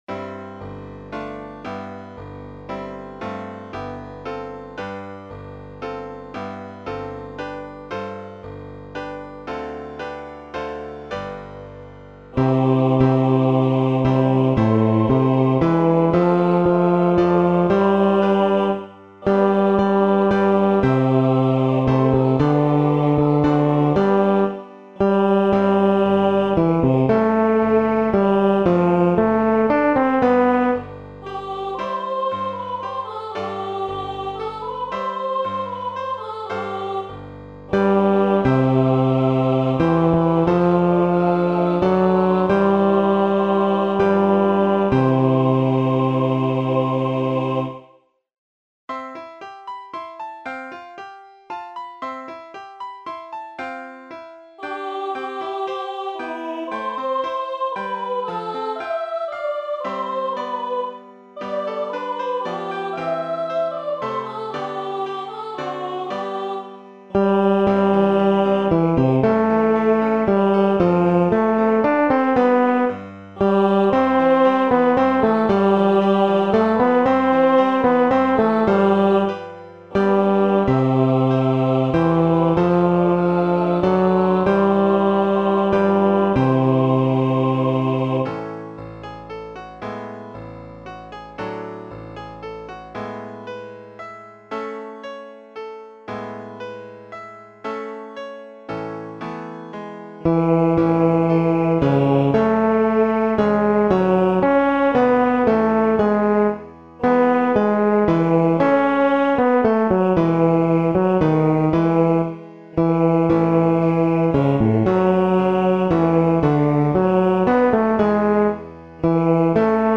バス（フレットレスバス音）